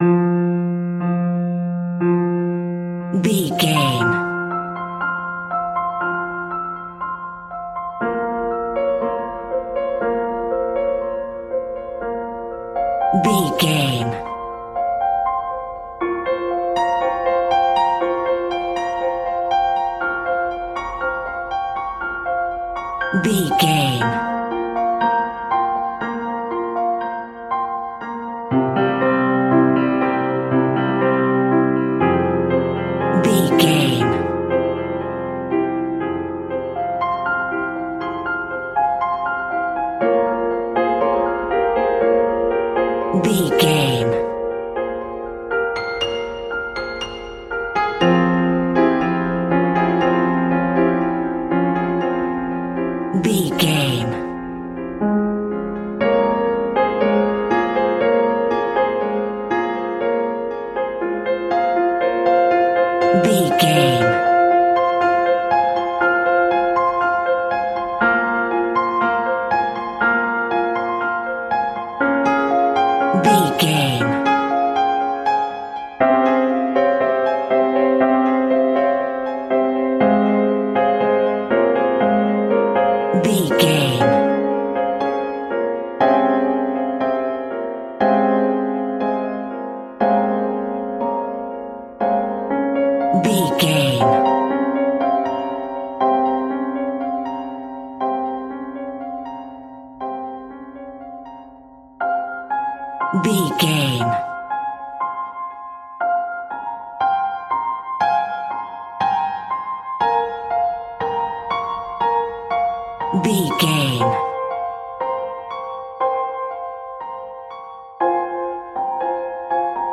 Aeolian/Minor
tension
ominous
dark
eerie
Scary Piano